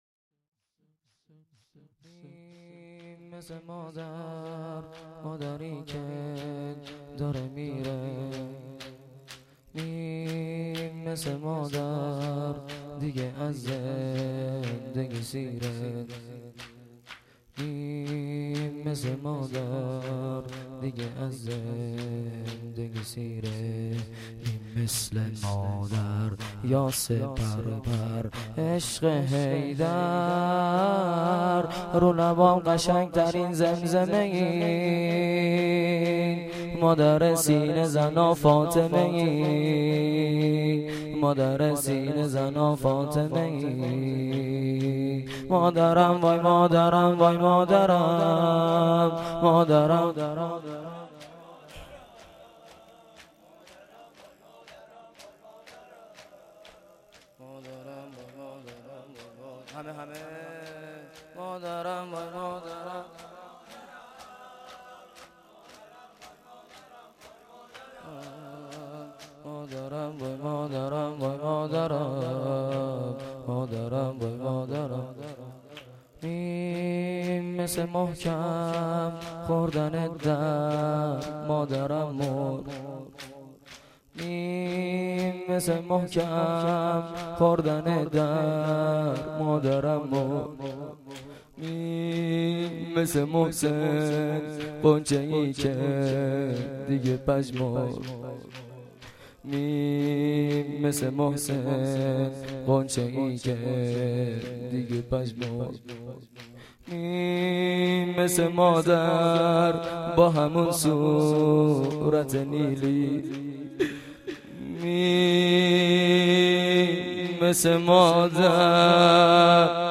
فاطمیه92(شب دوم)